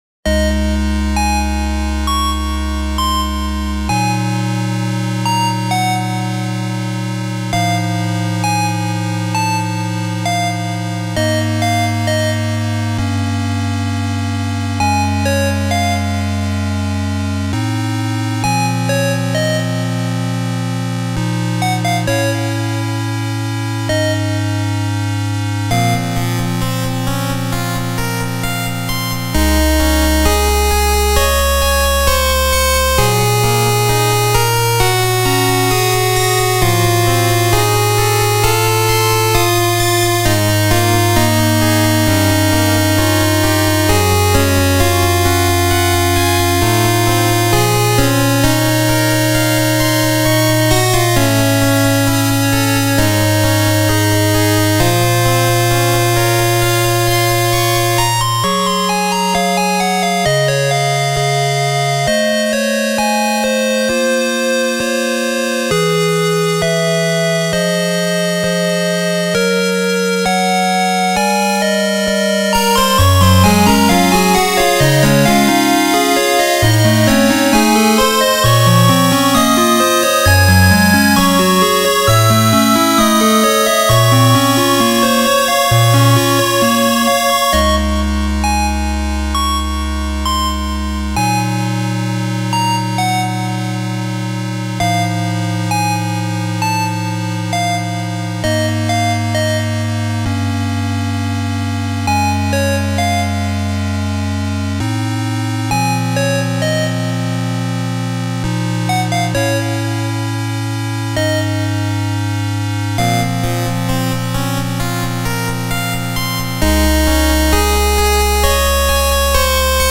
ジャンルファミコン風、8-Bit
BPM６６
使用楽器8-Bit音源
ファミコンのシステムと同様に４和音構成でアレンジしたため、実機の音質にとても近い響きとなっております。